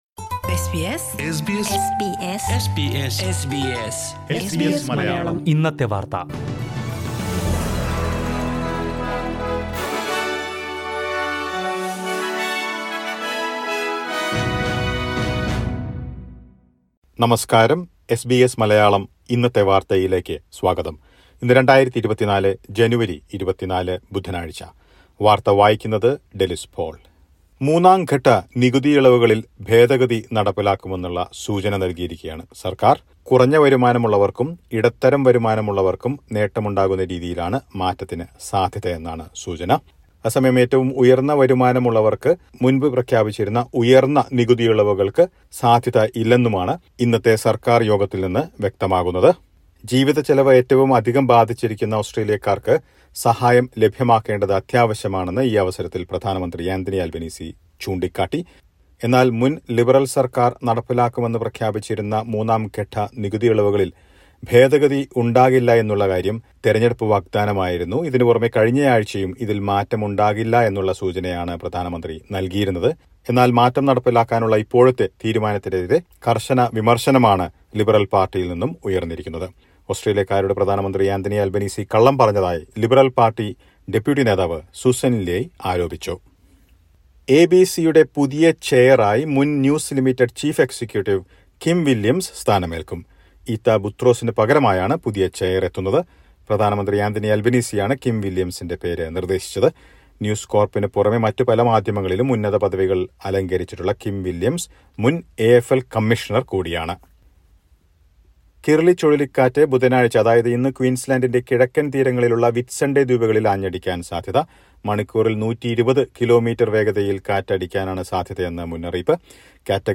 2024 ജനുവരി 24ലെ ഓസ്‌ട്രേലിയയിലെ ഏറ്റവും പ്രധാനപ്പെട്ട വാര്‍ത്തകള്‍ കേള്‍ക്കാം.